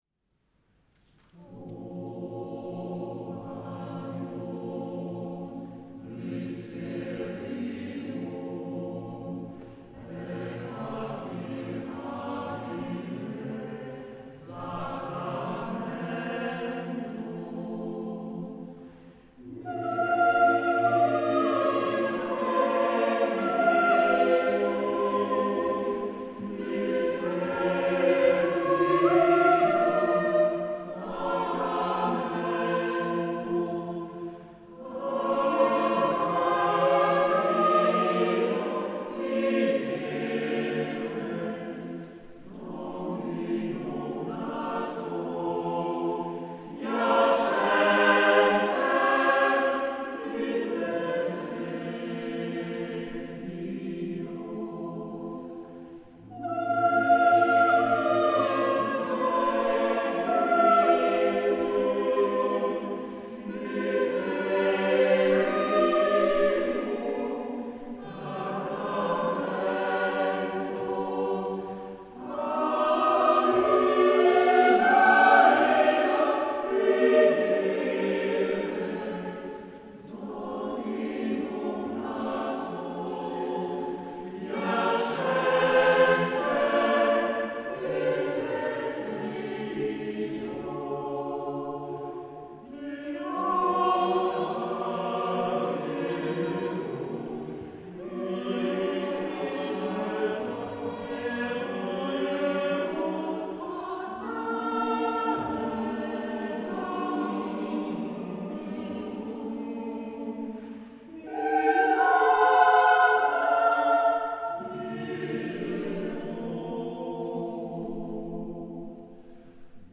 Die 1952 entstandene Motette beschwört eine sehr innige, vertrauliche Atmosphäre.
Bemerkenswert sind unerwartete harmonische Rückungen, als wollte der Komponist den Zuhörer in andere Sphären entführen. Ein langsam schaukelnder Rhythmus durchzieht das Stück und deutet ein Wiegendlied an.
Mit dem Auricher Motettenchor haben wir dieses Stück in zwei Proben einstudiert und am 13.12.2025 im Rahmen der Motettenandacht in der Lambertikirche aufgeführt.